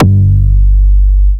01 Synther 1 G.wav